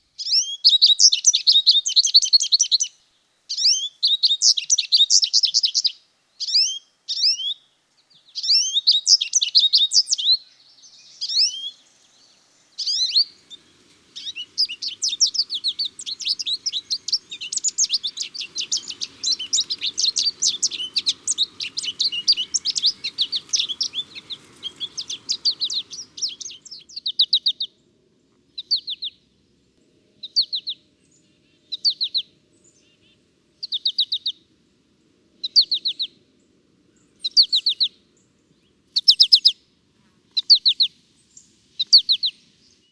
American Goldfinch
01-american-goldfinch.m4a